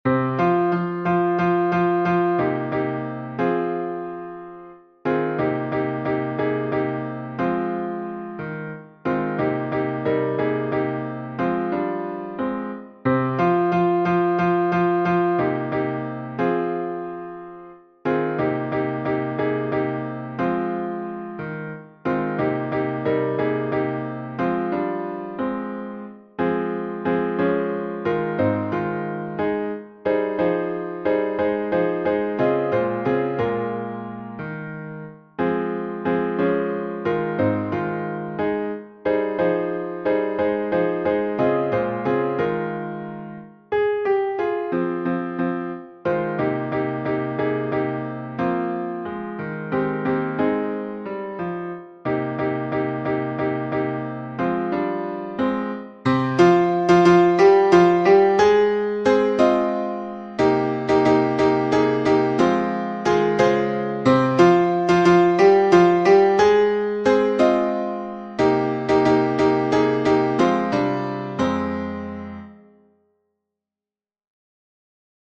Tutti
Nerea-izango-zen-Laboa-tutti-V3-1.mp3